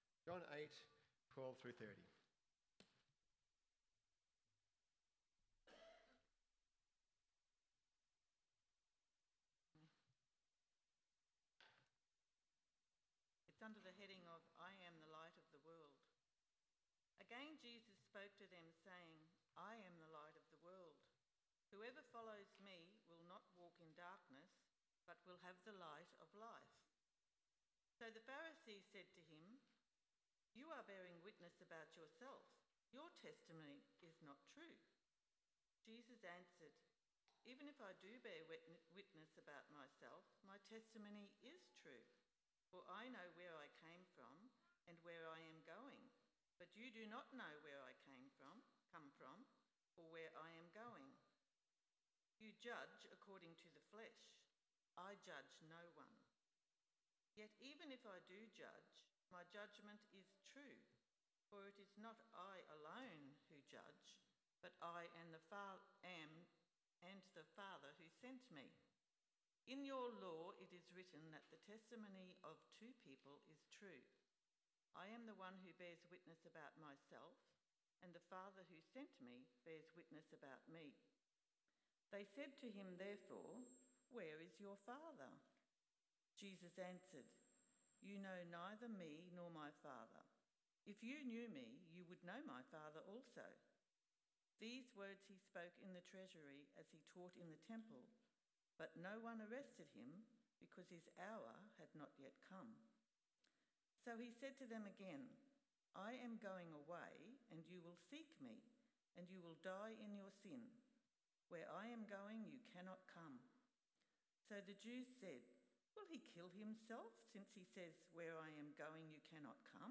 Have You Seen The Light? AM Service